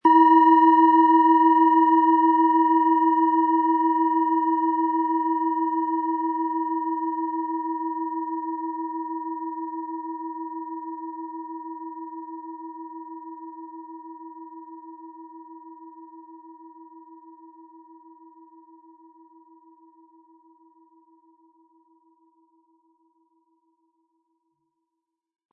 Hopi Herzton
Wie klingt diese tibetische Klangschale mit dem Planetenton Hopi-Herzton?
HerstellungIn Handarbeit getrieben
MaterialBronze